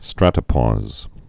(strătə-pôz)